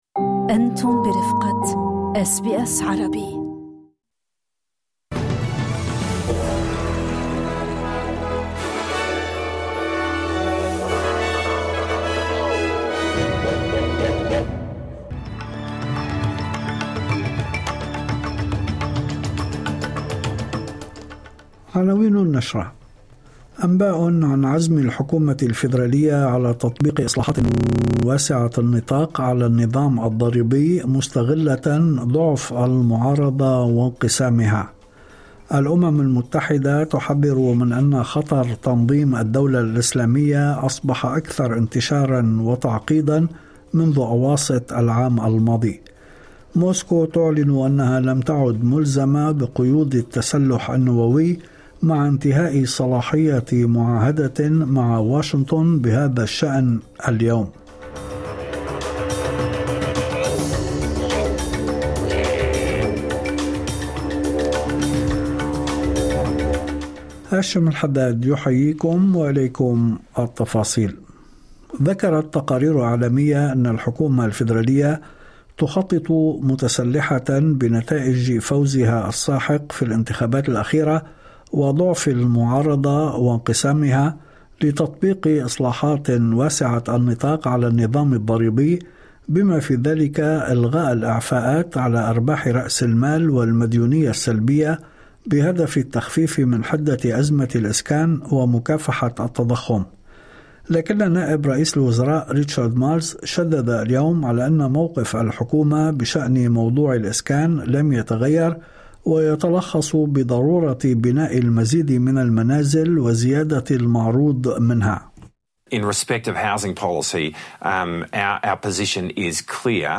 نشرة أخبار المساء 05/02/2026